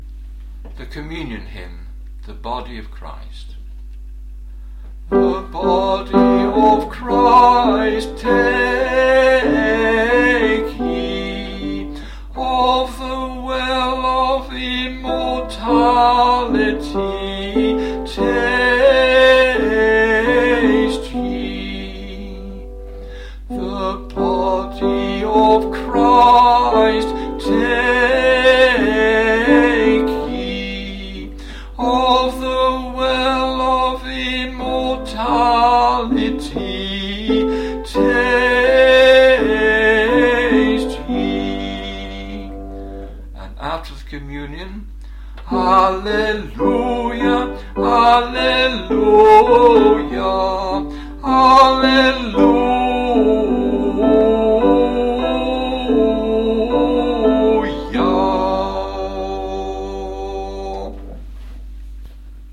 Communion Hymn
communion-hymn.mp3